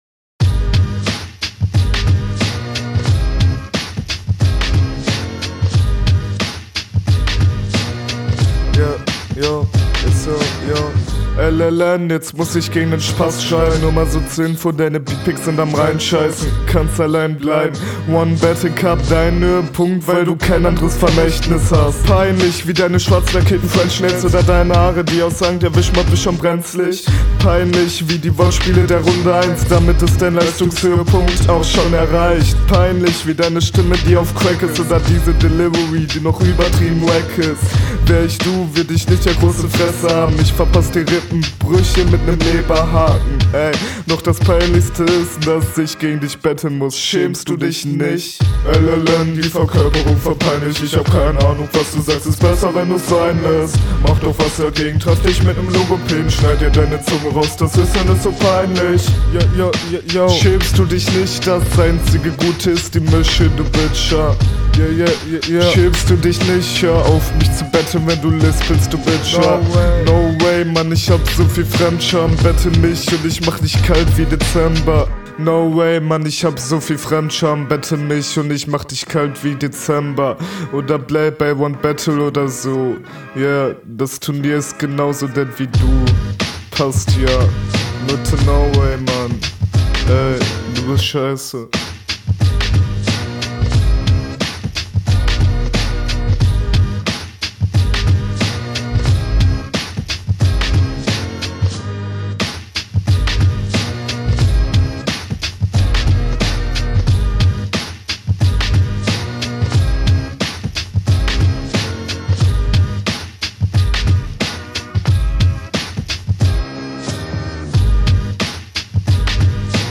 Schöner Kopfnicker-Beat. Leider dröhnt der Sound von den Vocals mir zu sehr.